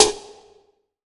SNARE 080.wav